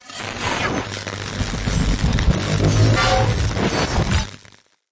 廉价电视静电
描述：电缆断开时我的电视产生的白噪声。
标签： 静态 收音机 模糊 电视 噪音 电视
声道立体声